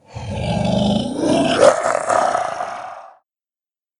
beast_roar_angry.ogg